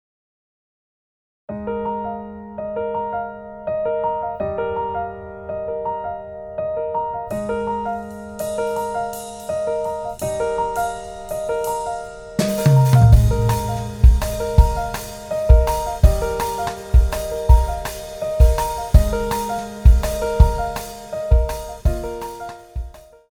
・コンプなしの音声